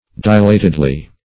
dilatedly - definition of dilatedly - synonyms, pronunciation, spelling from Free Dictionary Search Result for " dilatedly" : The Collaborative International Dictionary of English v.0.48: Dilatedly \Di*lat"ed*ly\, adv.
dilatedly.mp3